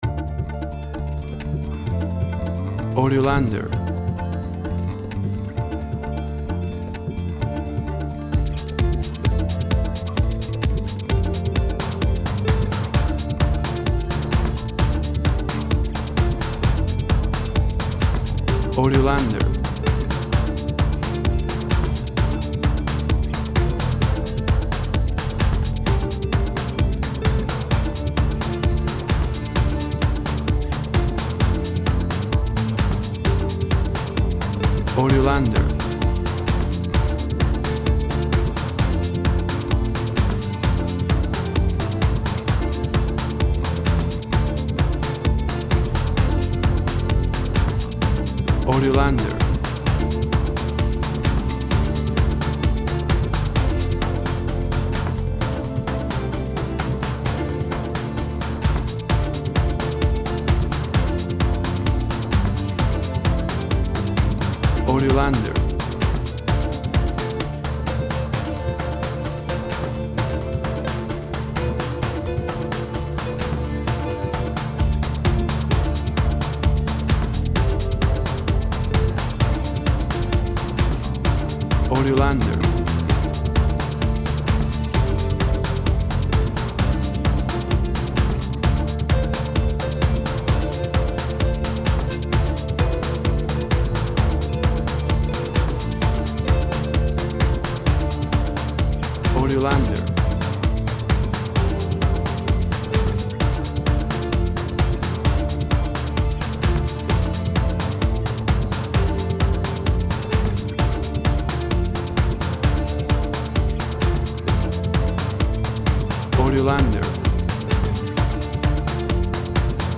Emtion music with Urban Sounds.
Tempo (BPM) 130